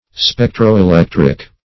Spectroelectric \Spec`tro*e*lec"tric\